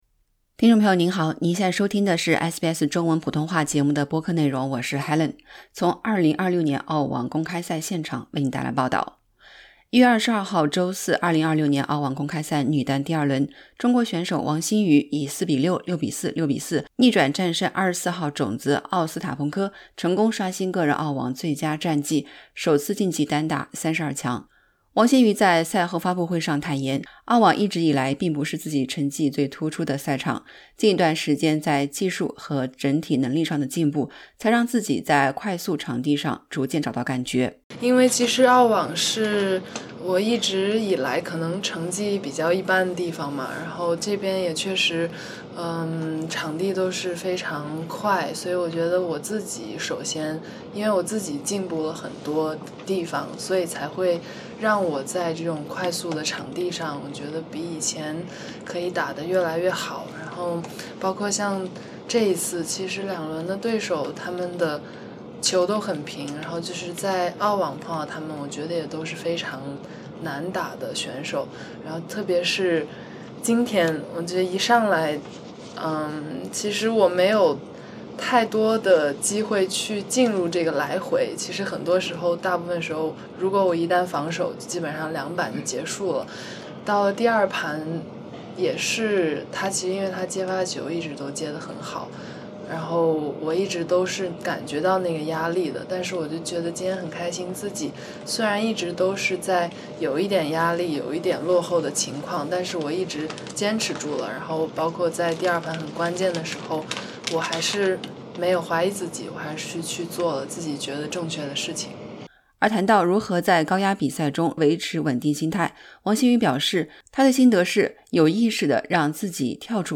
点击播放键收听完整采访 【澳网2026】王欣瑜首度晋级澳网女单32强 在墨尔本有“主场作战感” SBS Chinese 04:12 Chinese 周四（1月22日），2026年澳大利亚网球公开赛（AO）女单第二轮，中国选手王欣瑜以4-6、6-4、6-4逆转战胜24号种子叶莲娜·奥斯塔彭科（Jelena Ostapenko），成功刷新个人澳网最佳战绩，首次晋级单打32强。